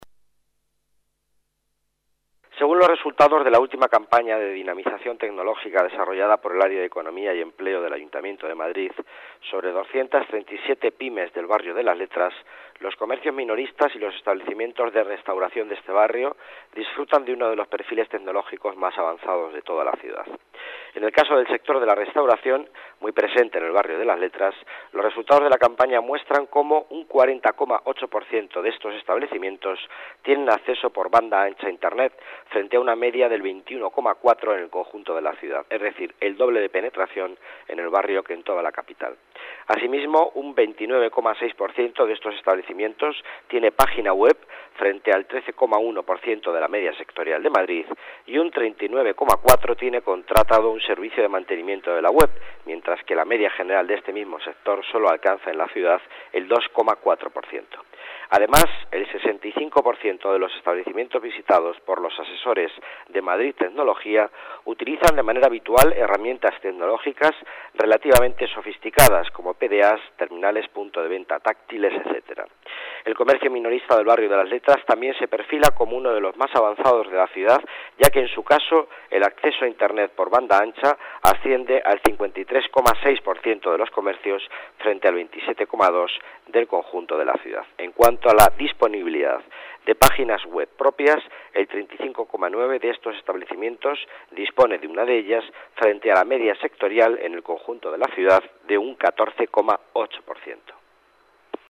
Nueva ventana:Declaraciones de Miguel Ángel Villanueva, delegado de Economía y Empleo